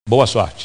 Silvio Santos desejando "Boa Sorte" no programa Show do Milhão.